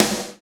SNR HARD 03R.wav